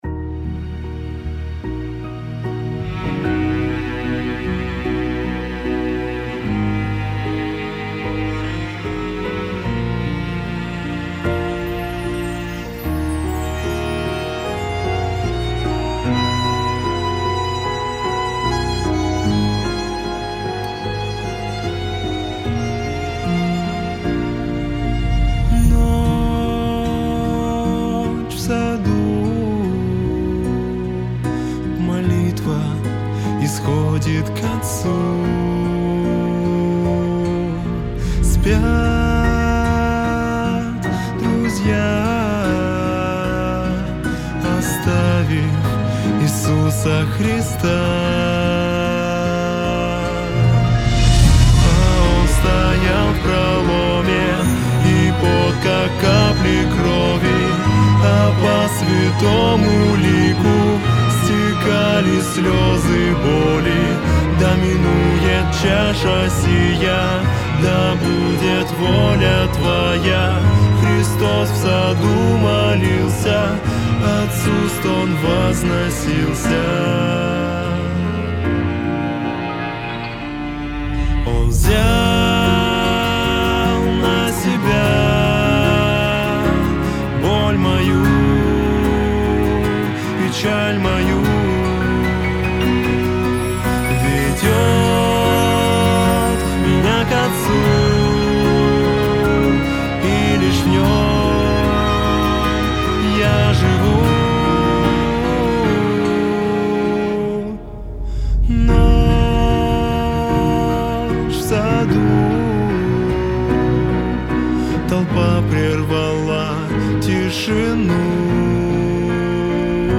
песня
3421 просмотр 3516 прослушиваний 351 скачиваний BPM: 75